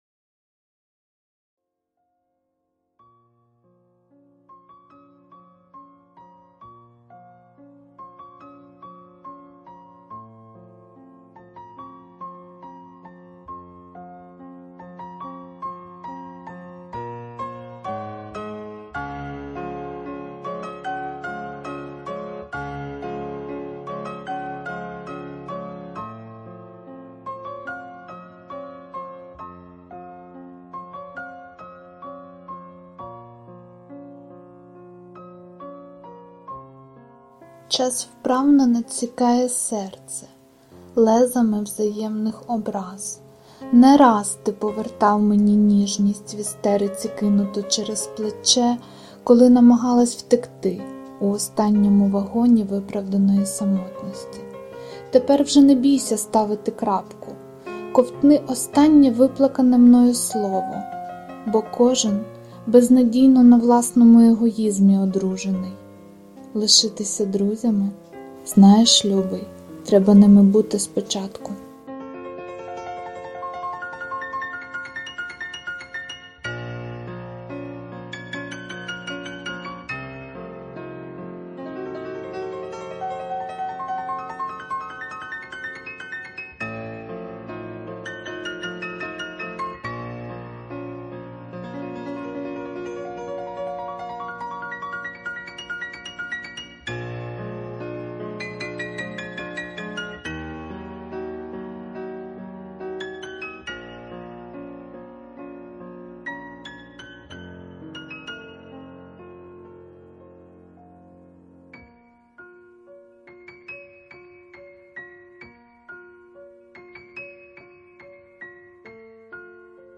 Рубрика: Поезія, Верлібр
Щирі слова, гарно підібрана музика.
Приємно слухати озвучення під музику фортепіано.